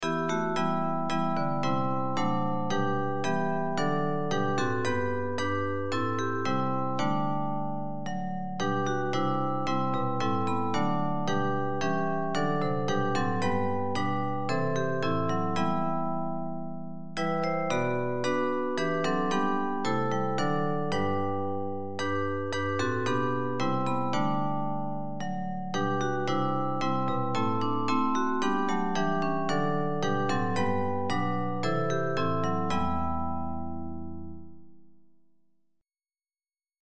public domain Christmas hymns